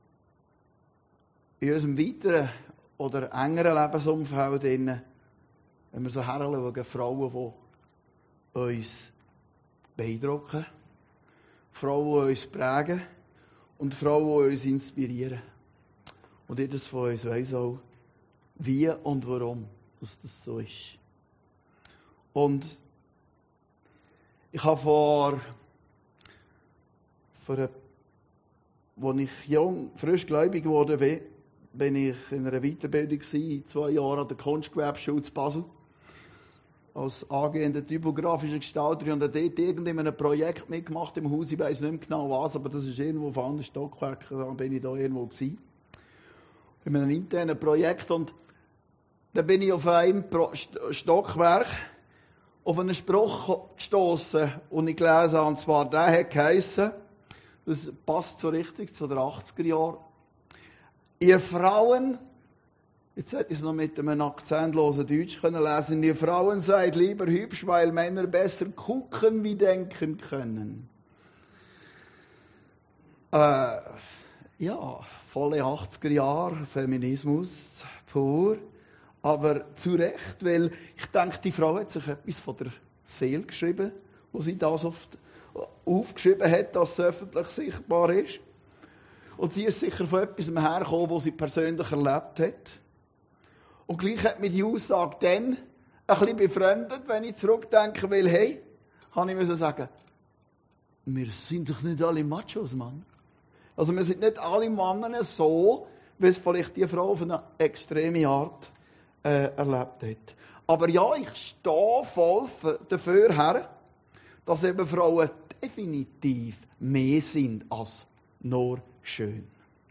Muttertagspredigt